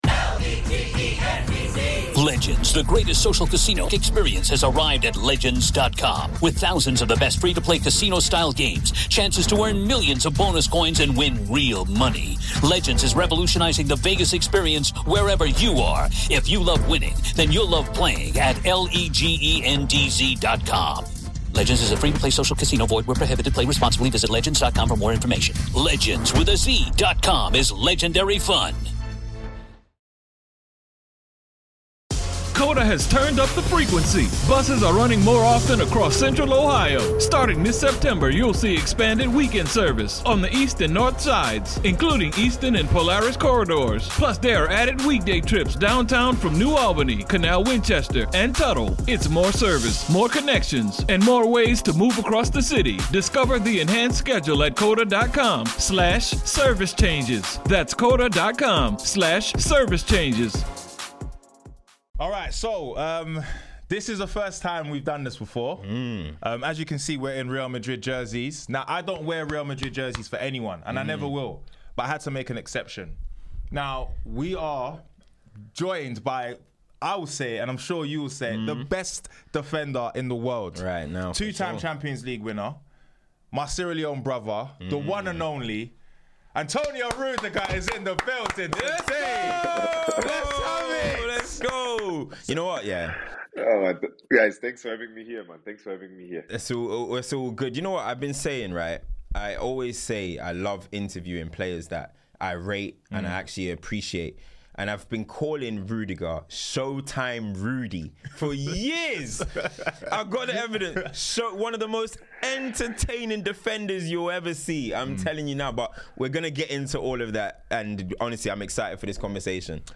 EXCLUSIVE INTERVIEW ⏐ ANTONIO RUDIGER - Why i left Chelsea, Life at Real Madrid & MUCH MORE - Ep 50